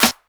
Snare_28.wav